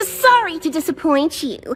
Worms speechbanks
BORING.WAV